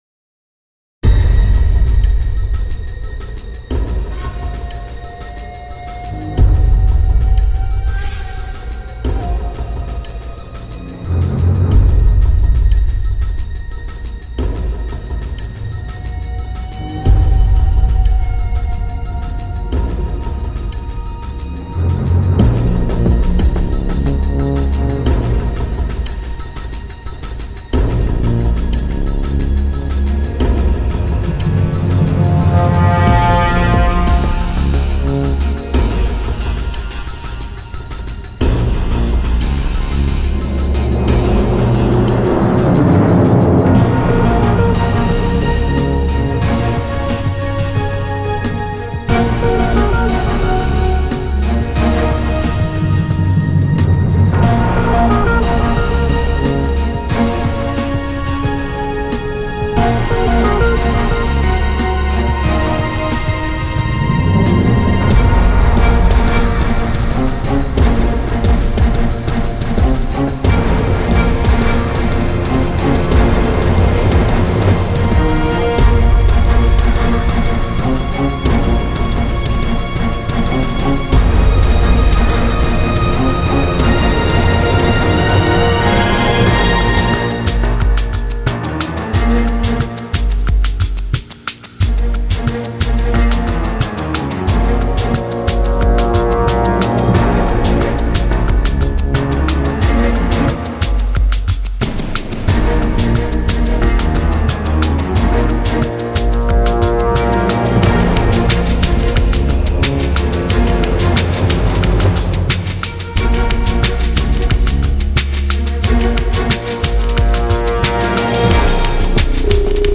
Звуковая музыка для сцены ограбления банка или магазина